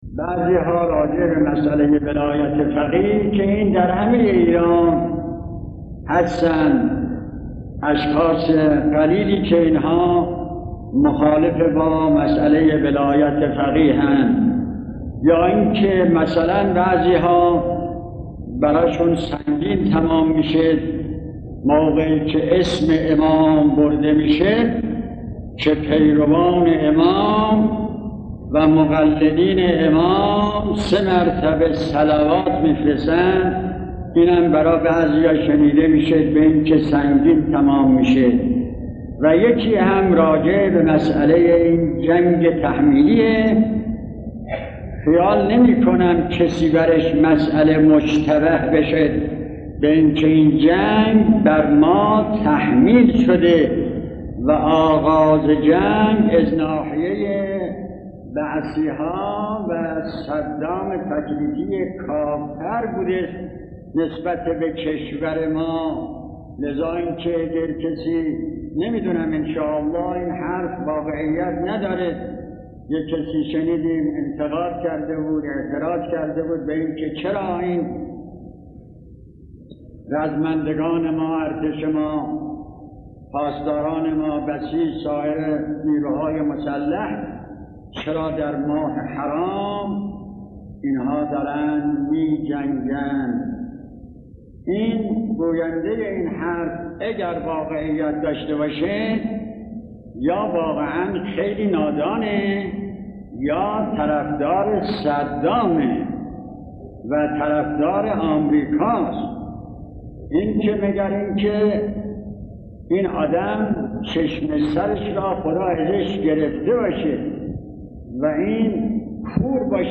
سخنان شهید محراب آیت الله اشرفی اصفهانی در خطبه های نماز جمعه هفدهم مهرماه 1360 را در نوید شاهد بشنوید.